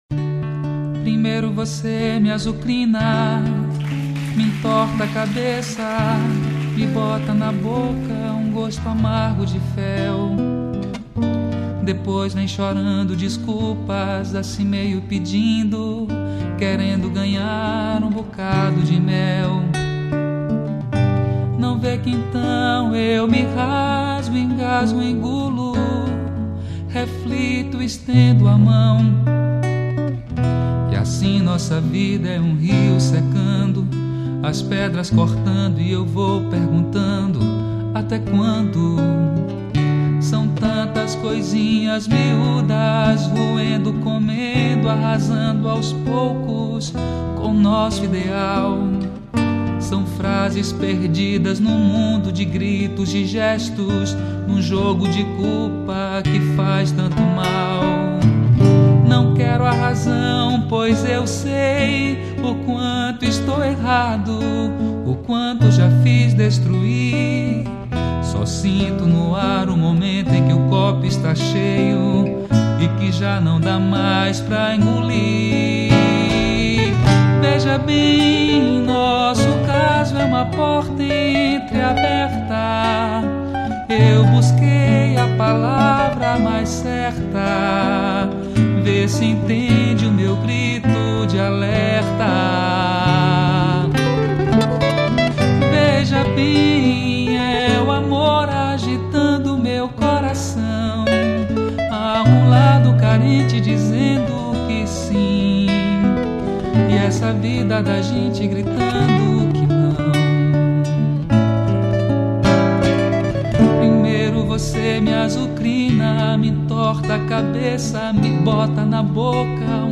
1885   04:31:00   Faixa:     Mpb